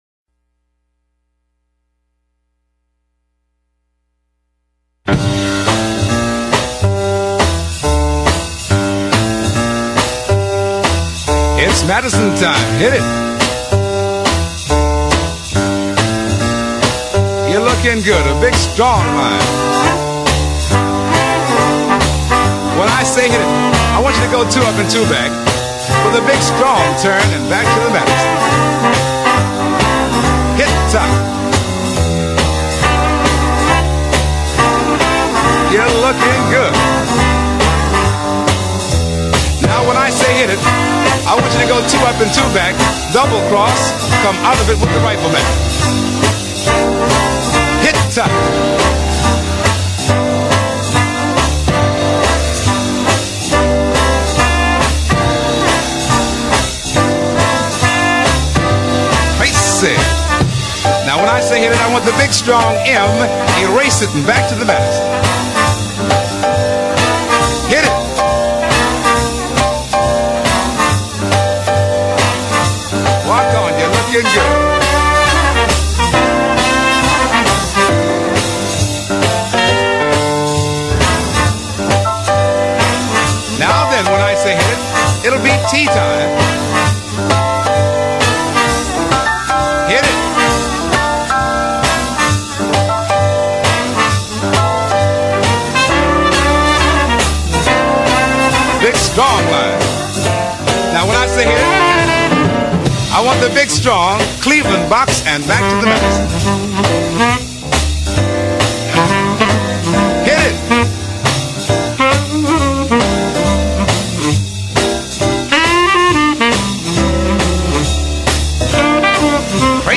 party record